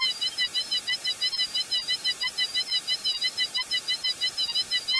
Noise 8.49
VibratoImpulsesNoise_HPR-M__Rem.mp3